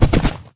gallop2.ogg